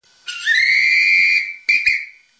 ca. 1950 Entwicklung des legendären "Doppelpfiffs" der fortan dann eingesetzt wurde, wenn ein Spiel "richtig zur Sache ging" und aufgrunddessen unterbrochen werden musste |
doppelpfiff-1.mp3